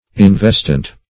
Search Result for " investient" : The Collaborative International Dictionary of English v.0.48: Investient \In*vest"ient\, a. [L. investiens, p. pr. of investire.] Covering; clothing.